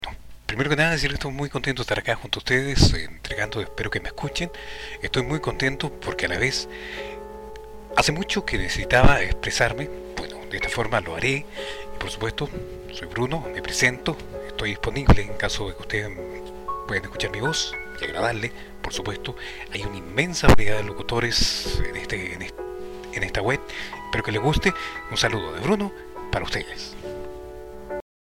Masculino
Espanhol - Chile